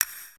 Perc (3).wav